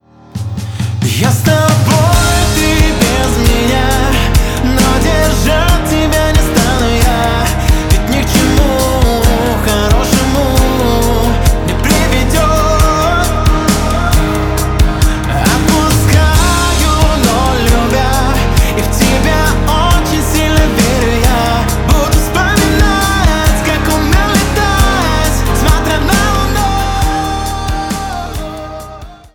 Поп Музыка
громкие